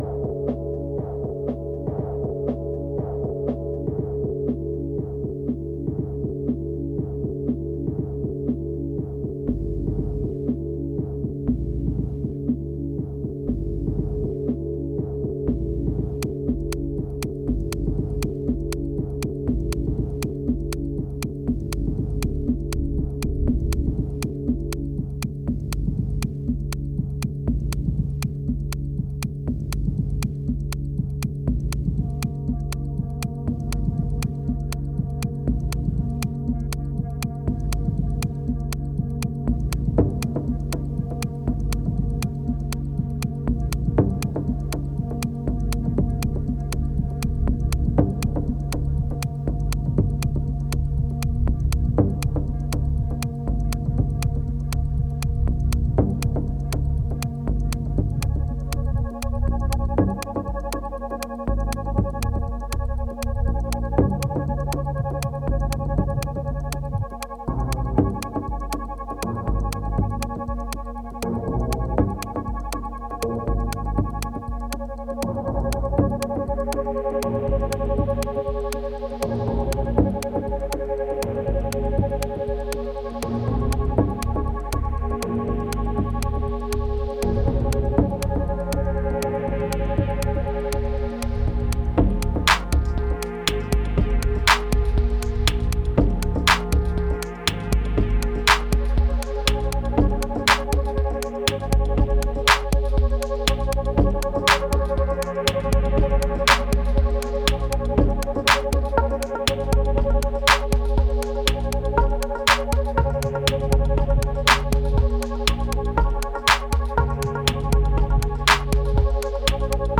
3205📈 - 29%🤔 - 120BPM🔊 - 2016-04-05📅 - -114🌟